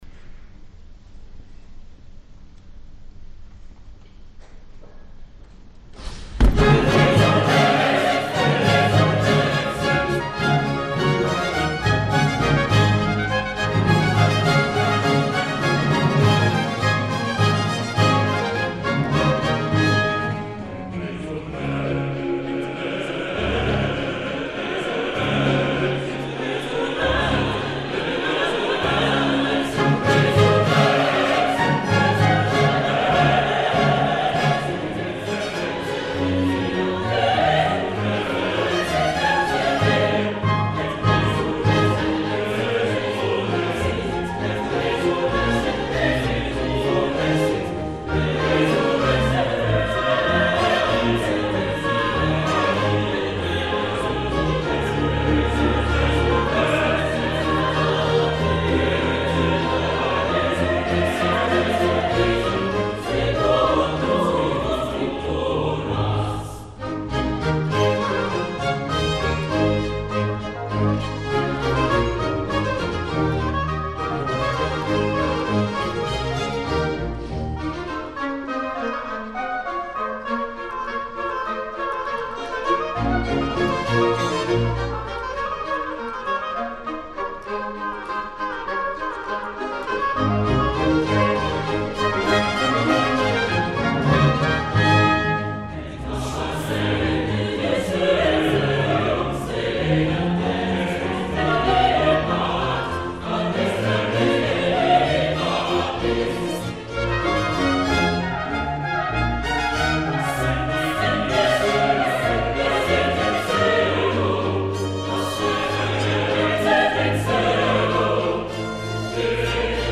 William Christie des del clavecí, va dirigir amb enèrgica jovialitat i calidesa en una versió que transmetia grandesa però també humanitat.
Les Arts Florissants
William Christie, director